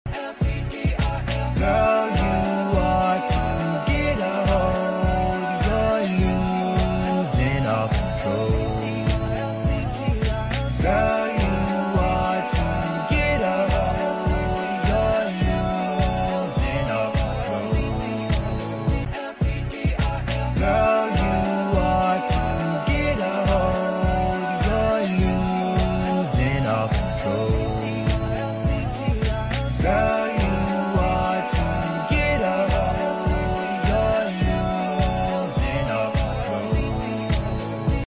house song